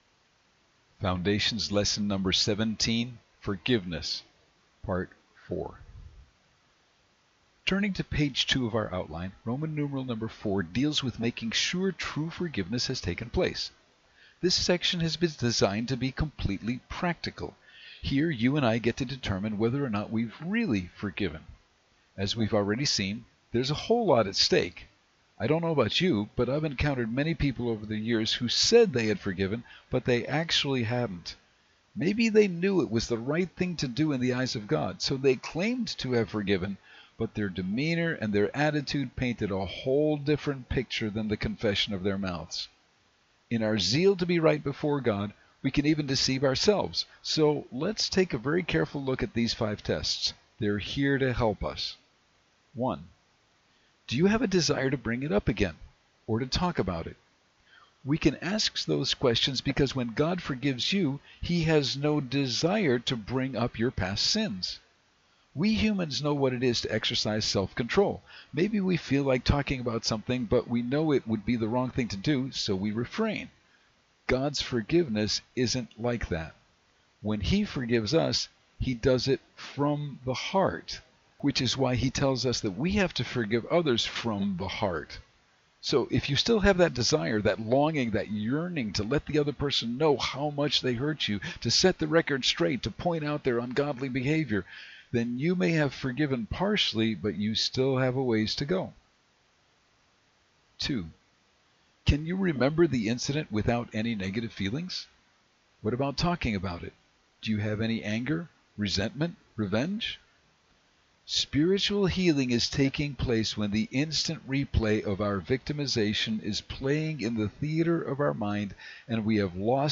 Spoken Lesson, Part Four